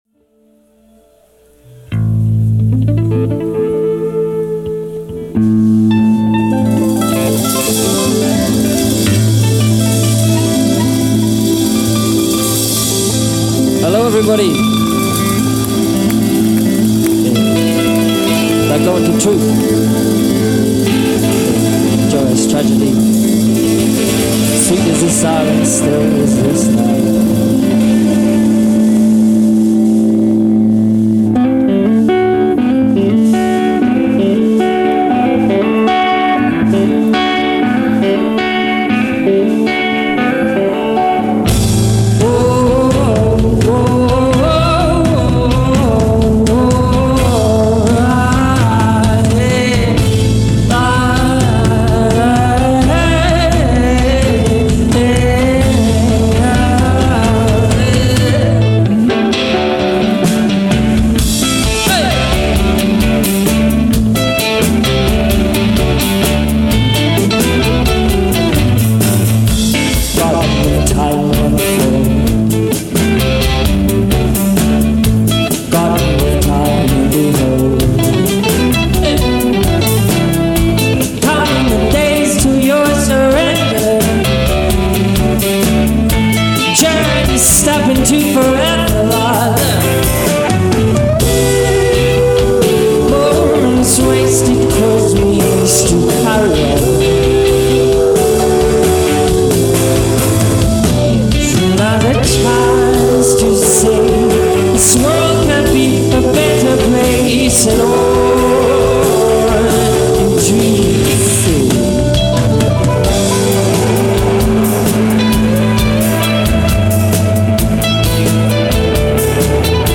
In Concert at Bayou Arts Center, Houston
Soundboard
Free-form/Jazz-Psych band